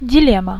Ääntäminen
IPA : /daɪˈlɛmə/ IPA : /dɪˈlɛmə/